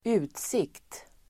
Uttal: [²'u:tsik:t]